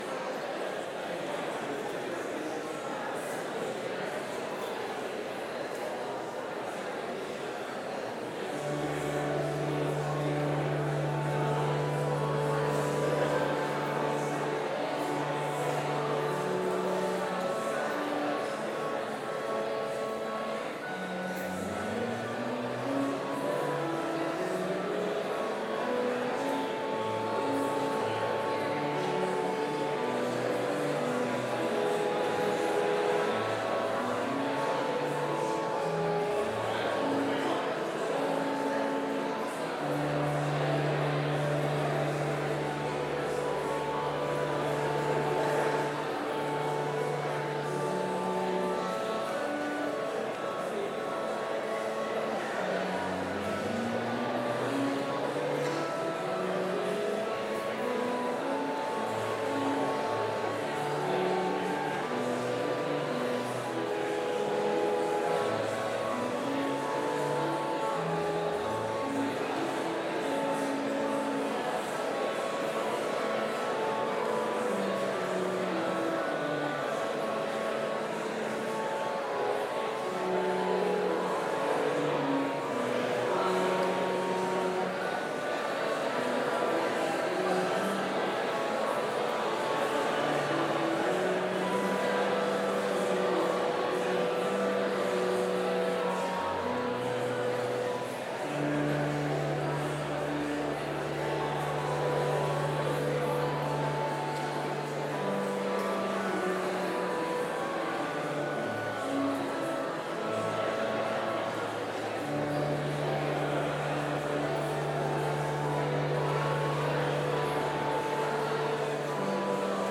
Complete service audio for Chapel - Wednesday, August 21, 2024